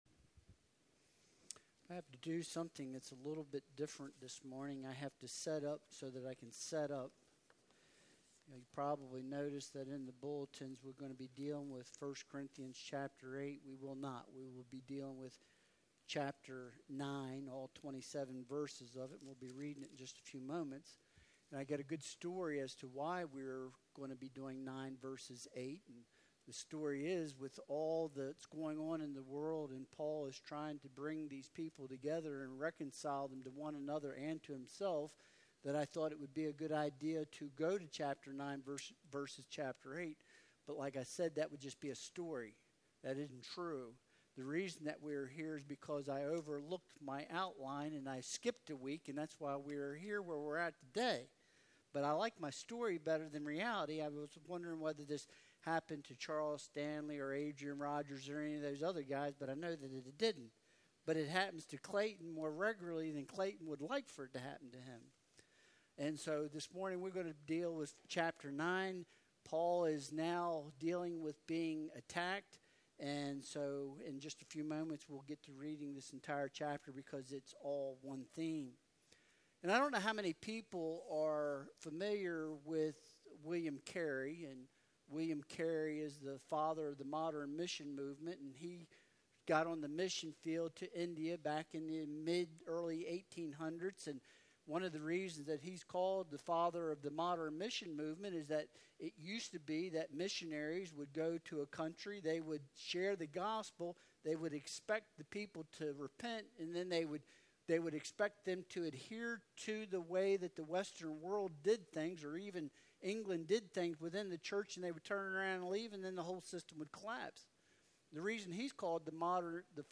Passage: 1 Corinthians 9.1-27 Service Type: Sunday Worship Service Download Files Bulletin « Are You Beating Down or Building Up?